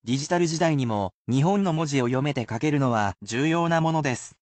You can repeat after the word pronunciation, but the sentences are at regular speed in order to acclimate those learning to the pace.